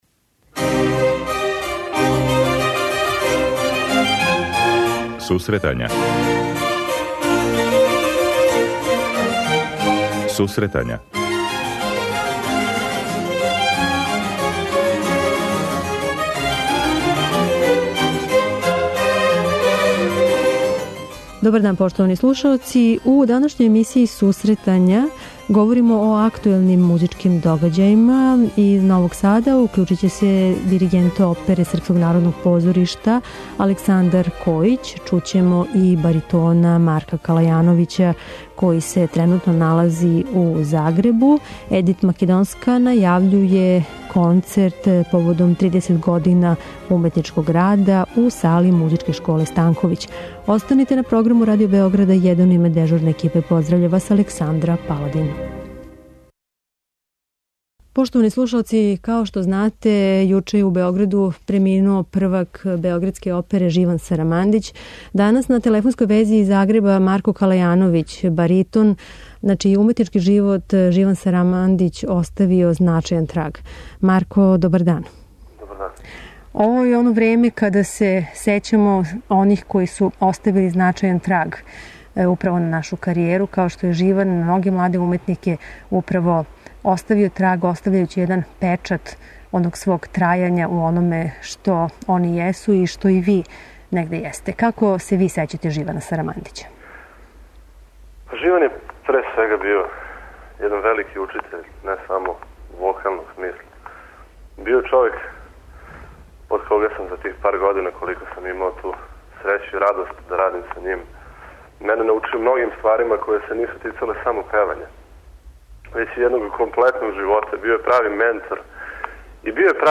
преузми : 26.08 MB Сусретања Autor: Музичка редакција Емисија за оне који воле уметничку музику.